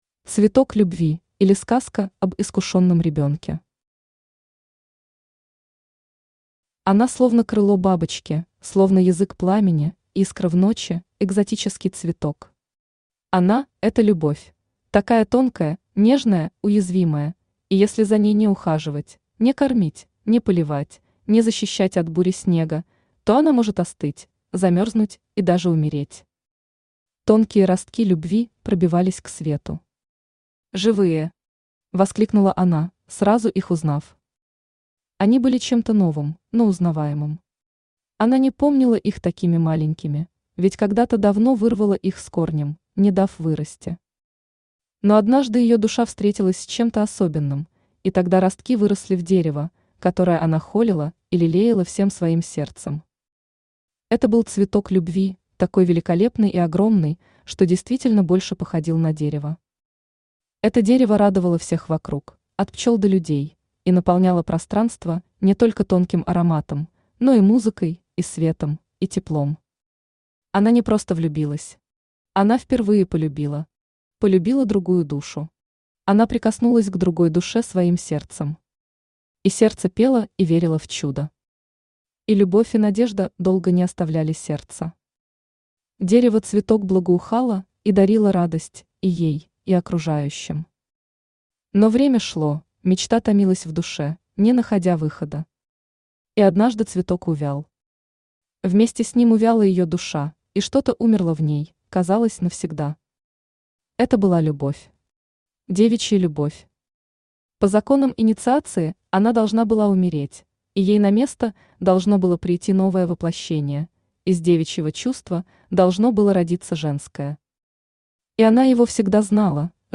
Аудиокнига Она. Цикл рассказов | Библиотека аудиокниг
Цикл рассказов Автор Аля Алев Читает аудиокнигу Авточтец ЛитРес.